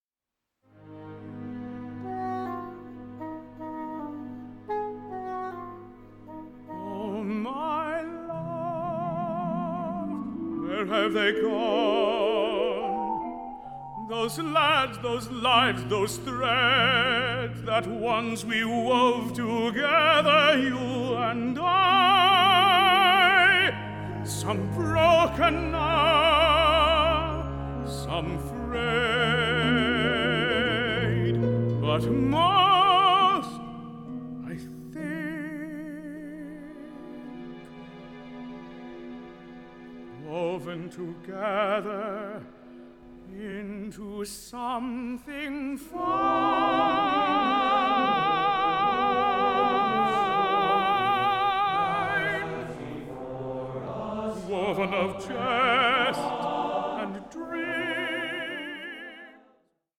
Film Soundtrack of the Opera